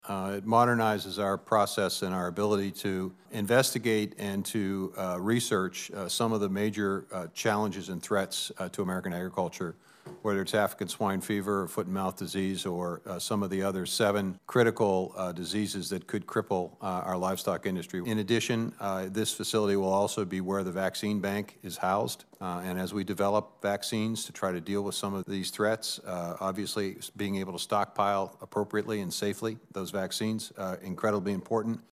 Vilsack spoke to the importance of the mission at NBAF.